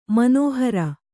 ♪ manōhara